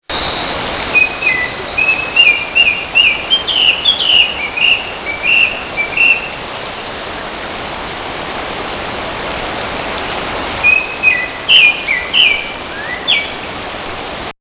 【環　境】　渓流
クロツグミの声　★　（MP3 146KB）